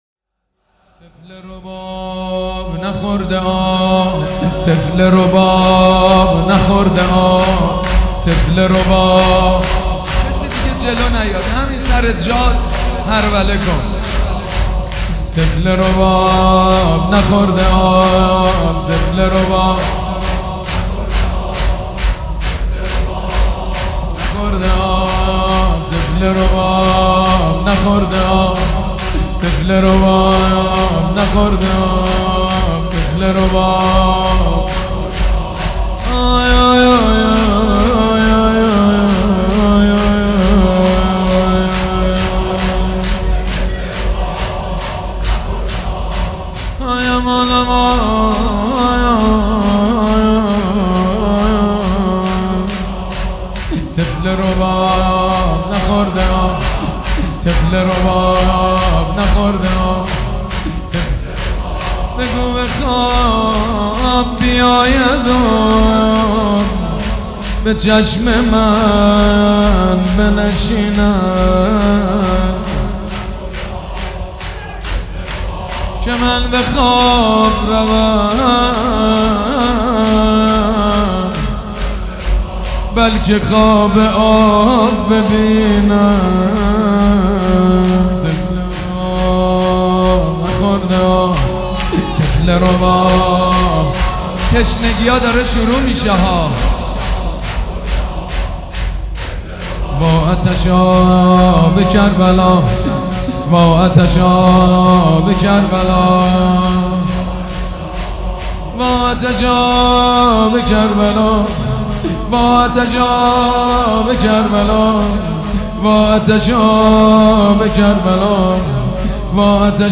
سید مجید بنی فاطمه